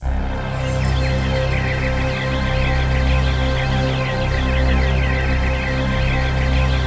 ATMOPAD06.wav